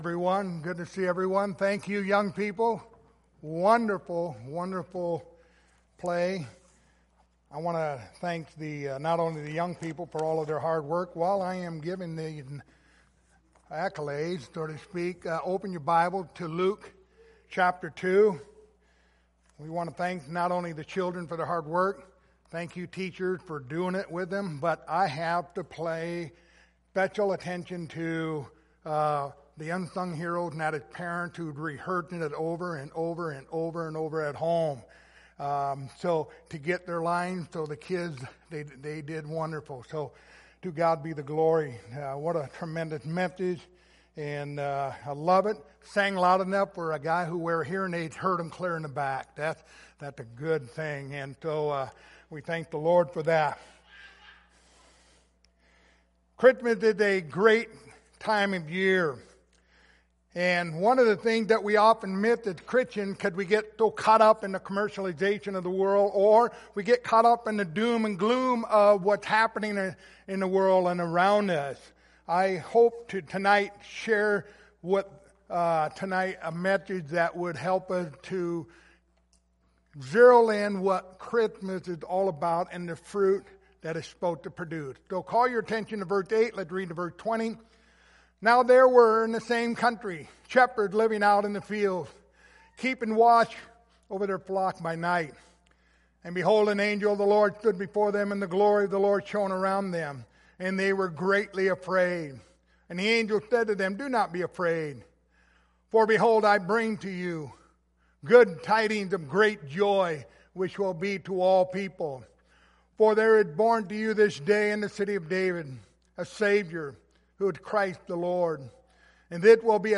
Christmas Passage: Luke 2:8-20 Service Type: Sunday Evening Topics